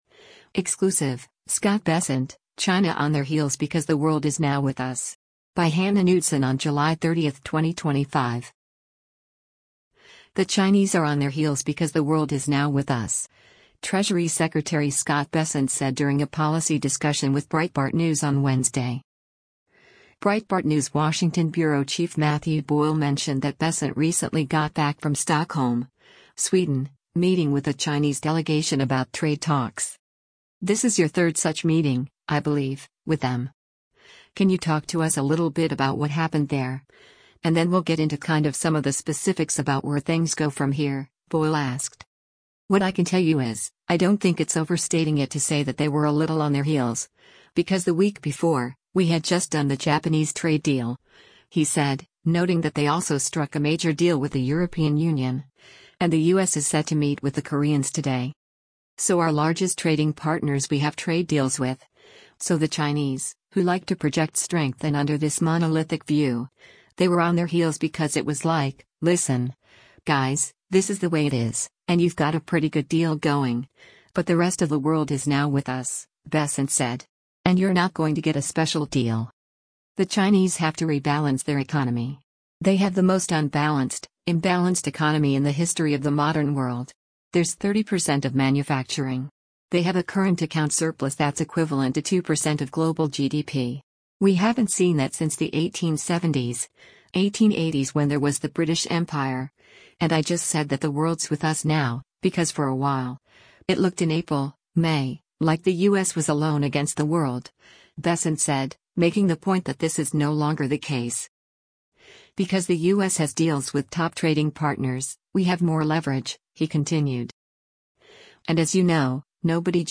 Treasury Secretary Scott Bessent during a policy discussion with Breitbart News on Wednesday, July 30, 2025.